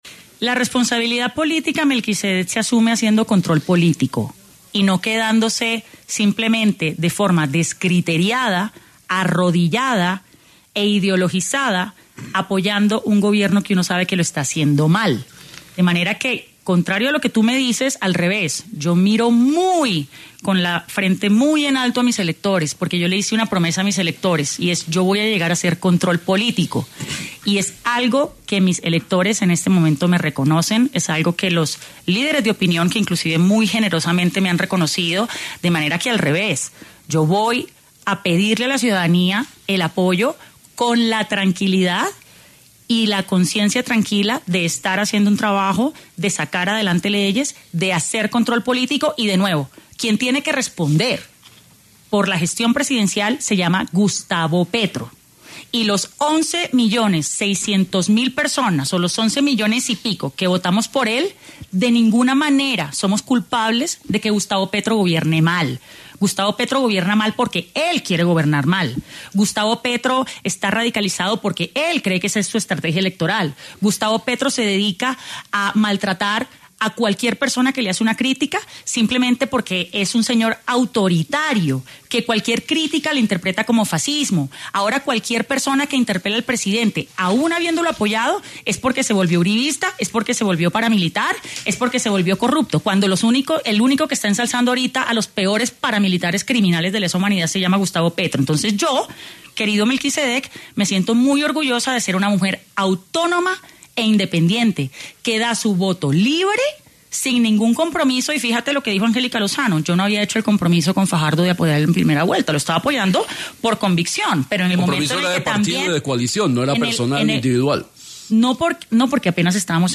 La representante a la Cámara cuestiona la gestión del presidente de la República al pasar por Sin Anestesia de La Luciérnaga.
La representante a la Cámara, Catherine Juvinao, pasó por los micrófonos de Sin Anestesia de la Luciérnaga, hablando de la gestión del actual gobierno, los proyectos de ley que se tratan en el congreso, y lanzó una fuerte crítica hacia el presidente Gustavo Petro.